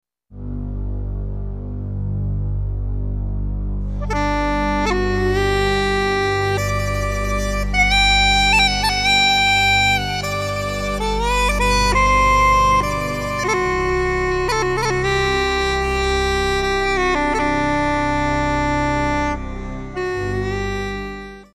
Cornemuse irlandaise (2002), un petit essai synthé-uilleann pipe avec une imitation d'air lent de Bill Whelan
pipe-extrait-01.mp3